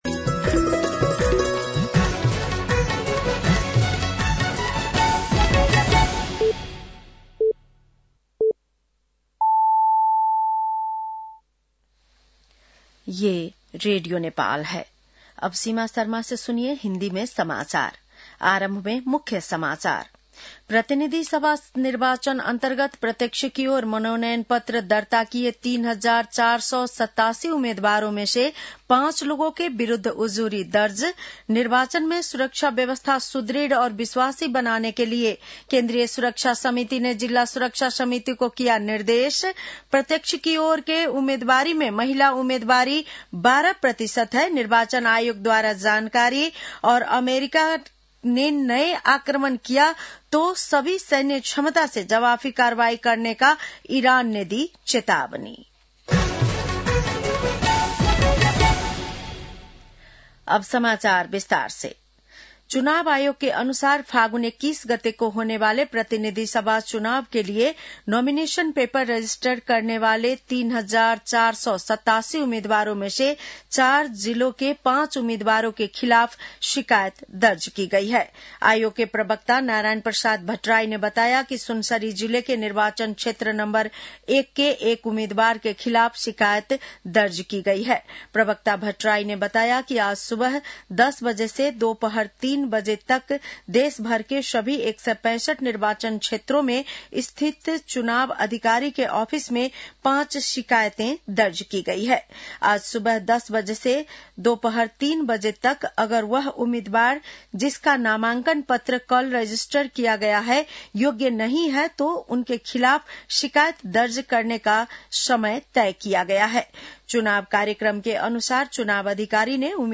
बेलुकी १० बजेको हिन्दी समाचार : ७ माघ , २०८२